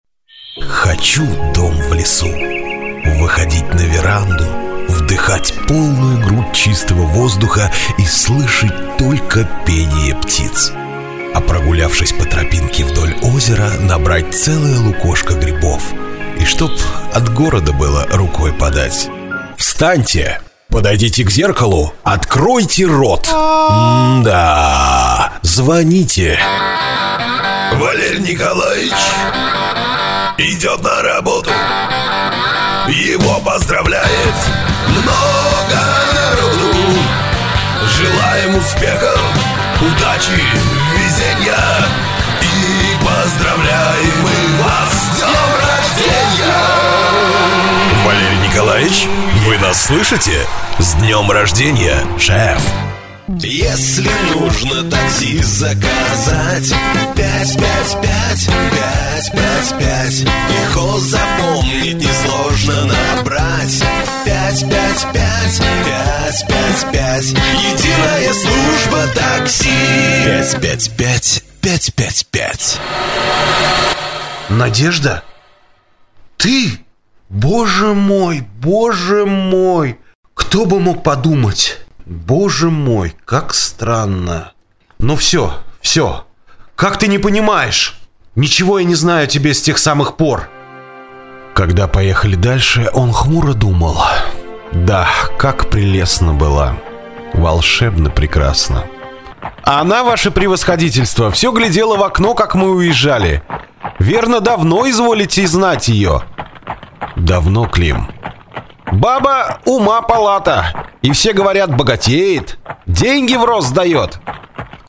Низко (ближе к басу), чистый баритон, баритон с хрипотцой, звонкий голос рядом с сопрано.
пред усилитель DBX 286A, микрофон SE2200T(tube), конвертер Stainberg
Демо-запись №1 Скачать